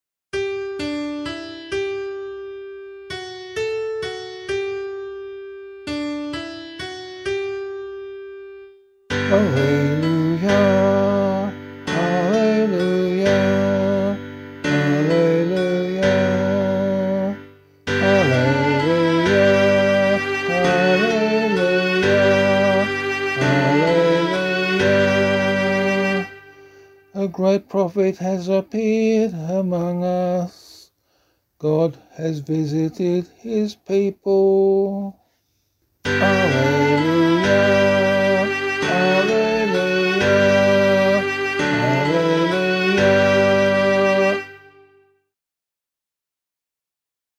Gospel Acclamation for Australian Catholic liturgy.
046 Ordinary Time 12 Gospel B [LiturgyShare F - Oz] - vocal.mp3